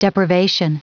Prononciation du mot depravation en anglais (fichier audio)
Prononciation du mot : depravation